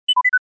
robot
robot.mp3